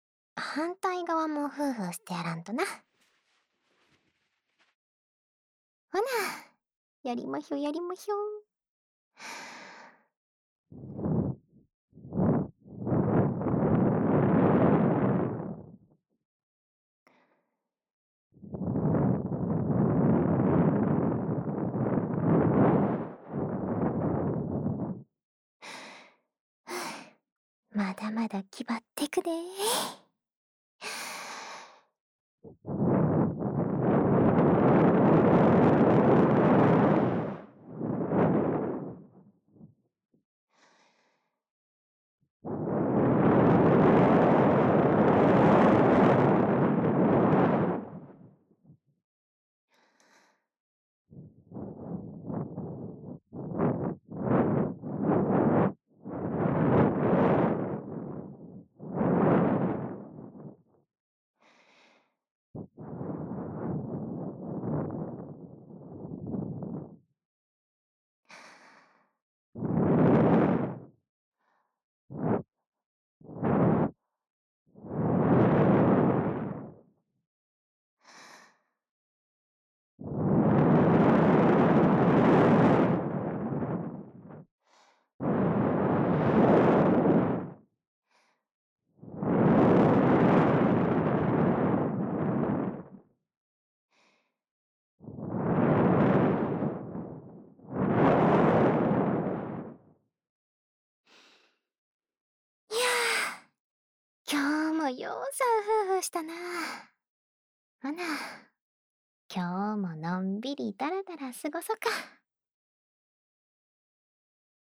家中喝酒 ASMR
关西腔的同学
el90_14_『附录。另一侧的耳朵也得帮你吹下才行！（吹耳朵左）』.mp3